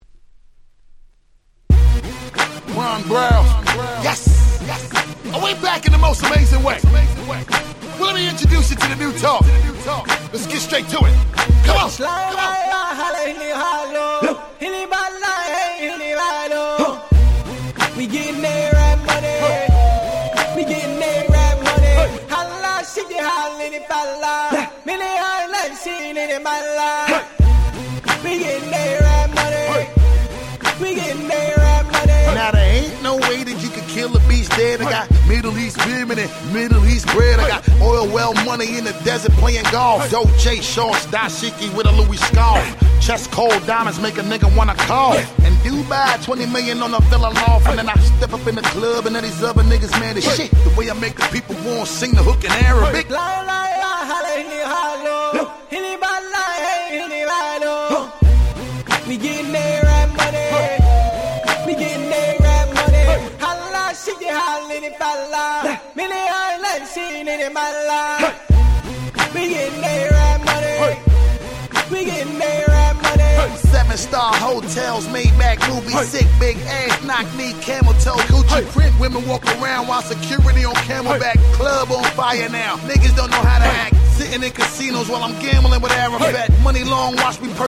08' Big Hit Hip Hop !!!!!